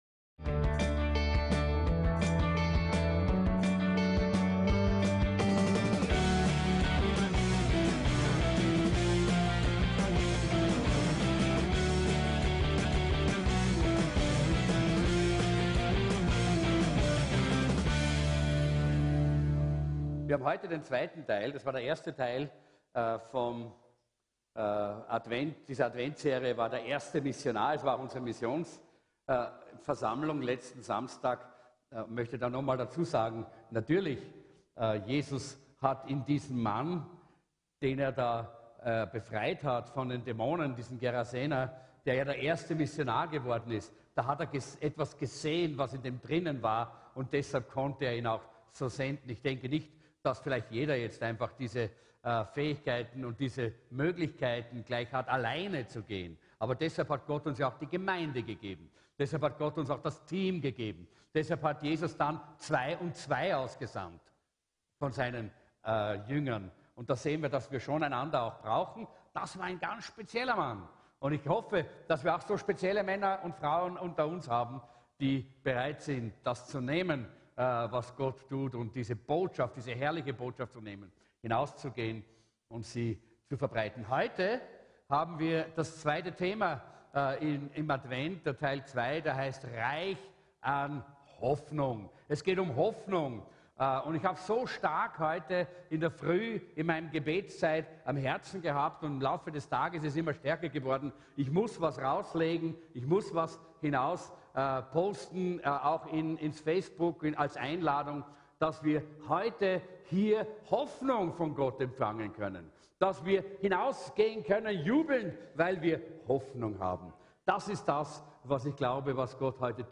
REICH AN HOFFNUNG " ADVENT " ( 2 ) ~ VCC JesusZentrum Gottesdienste (audio) Podcast